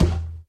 mob / irongolem / walk3.ogg
walk3.ogg